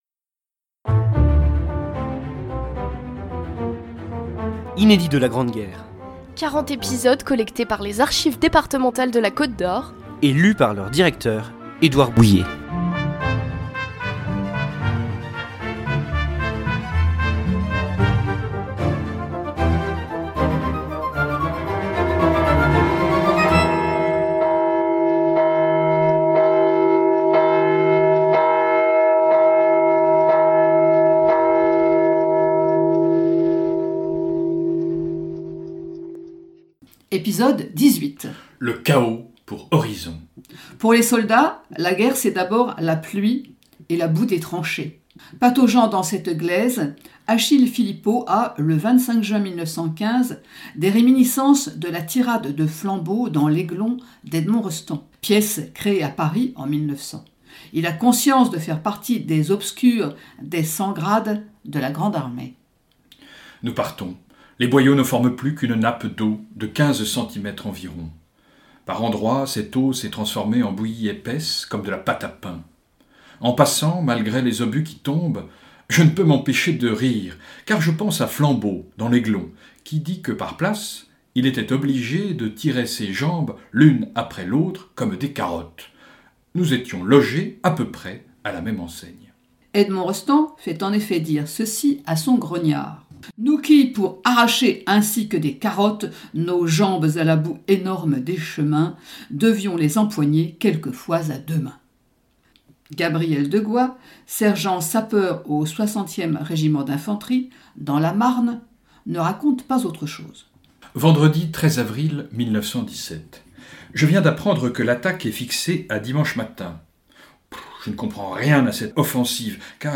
Un feuilleton de lettres et de journaux intimes à suivre jusqu’au 11 novembre 2018, pour célébrer le centenaire de l’armistice de la Grande Guerre, signé à Rethondes le 11 novembre 1918 !